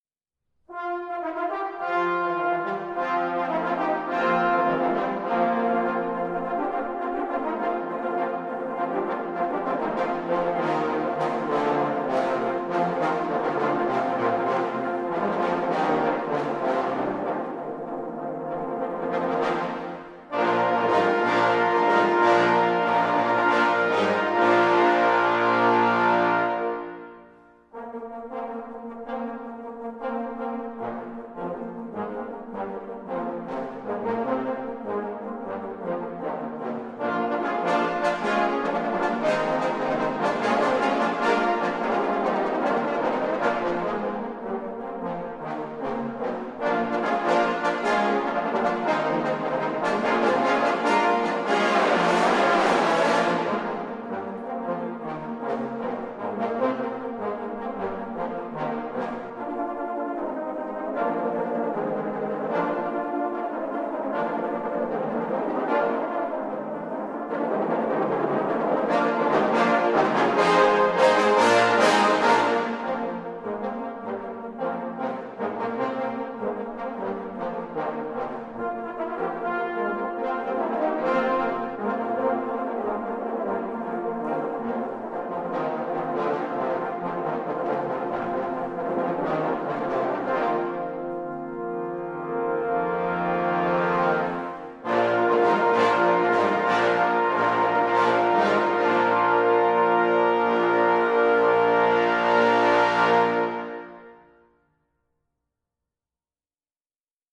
Voicing: Trombone Choir